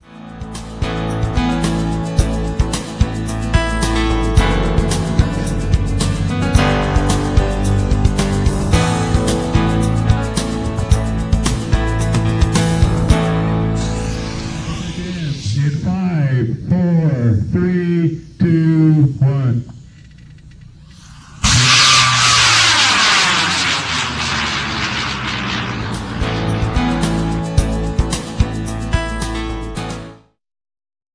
See some of the cool video taken at LDRS: